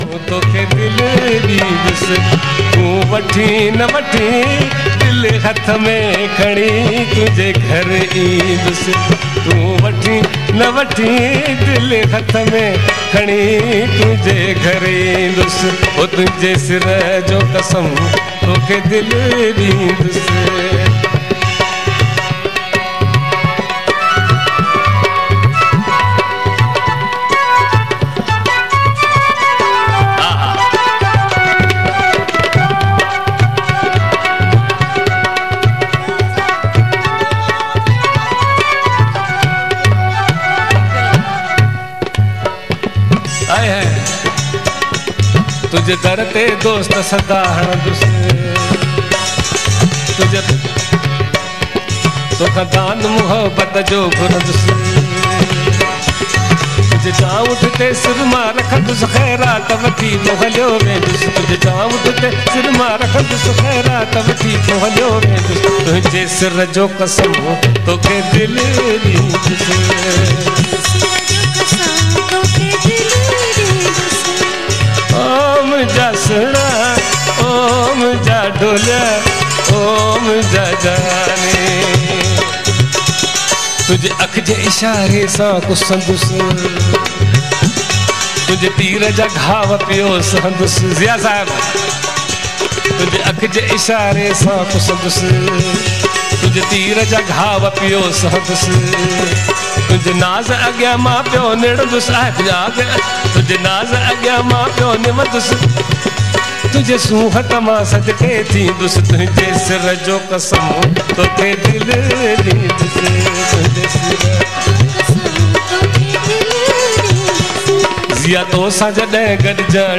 Format: LIVE
Live Performance